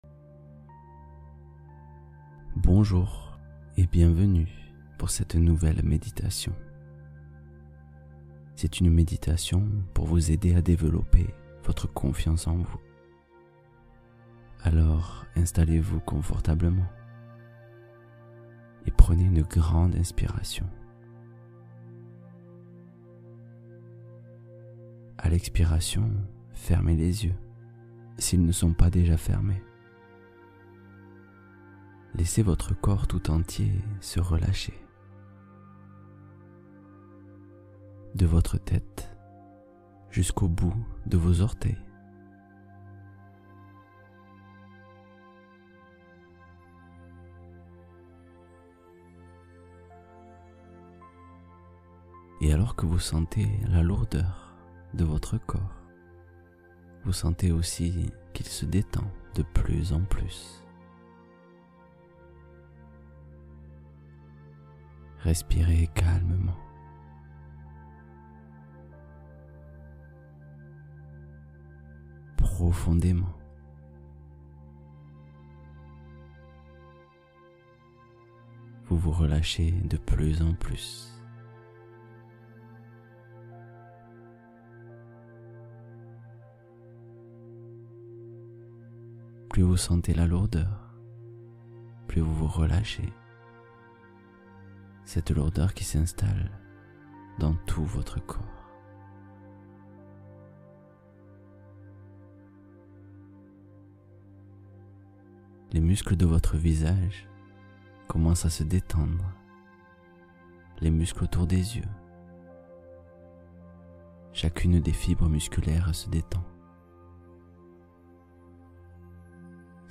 Confiance profonde : méditation intense pour se révéler